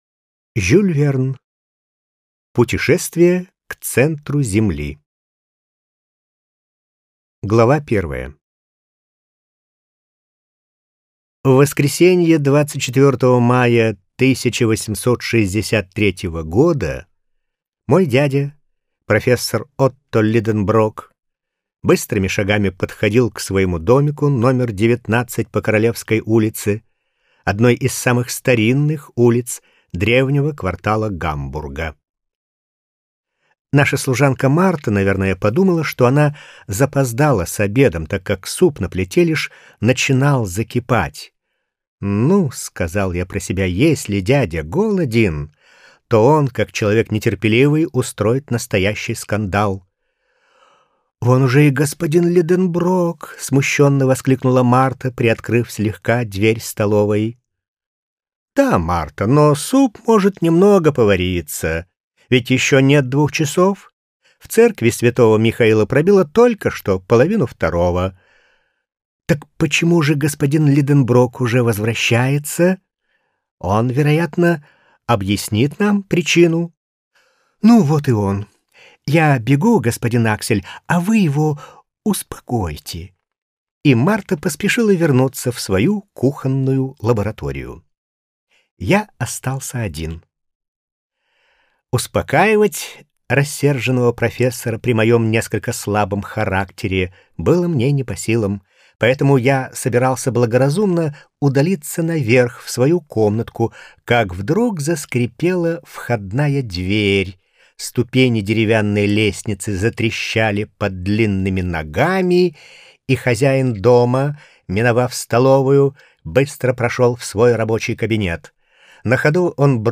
Аудиокнига Путешествие к центру Земли | Библиотека аудиокниг